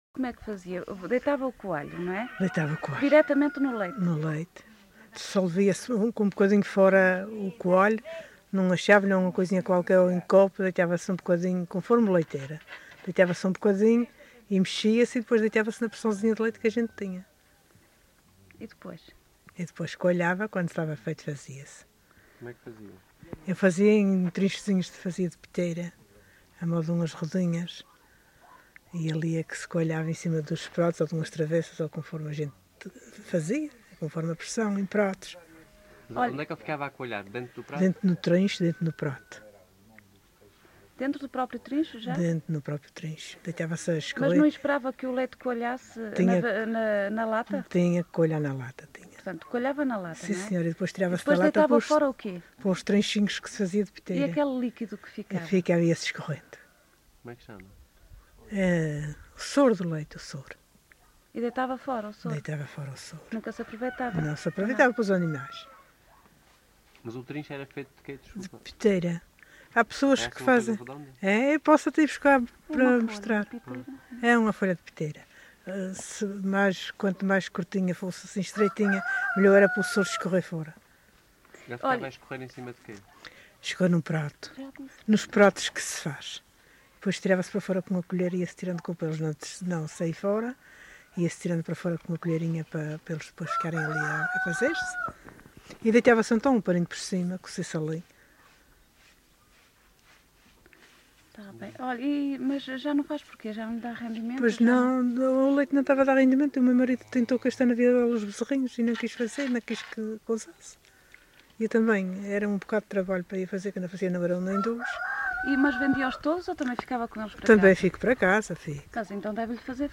LocalidadeSanta Cruz da Graciosa (Santa Cruz da Graciosa, Angra do Heroísmo)